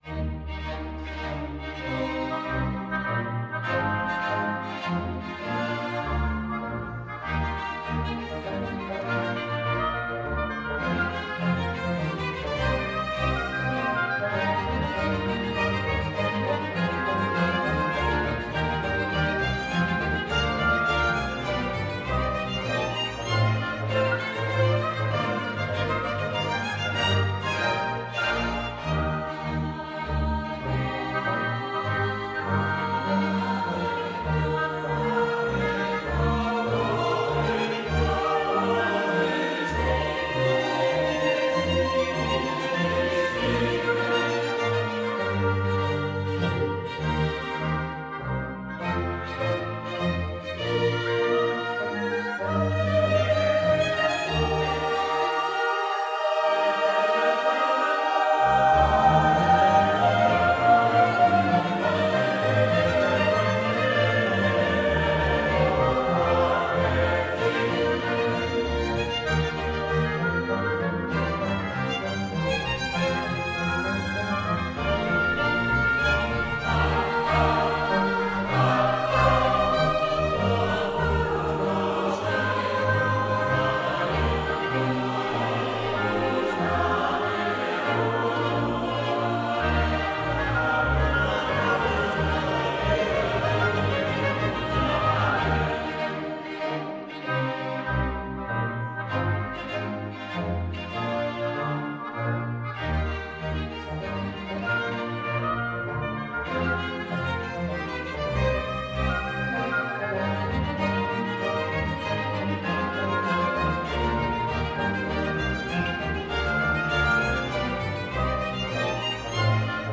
Bach: uma cantata para o início do Advento
cantatafinale.mp3